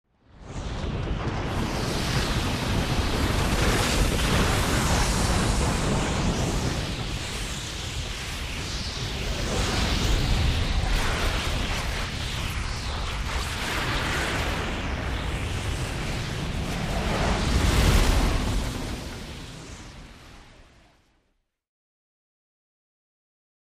Landslide Or Avalanche Movement